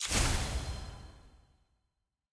sfx-eog-eternals-burst.ogg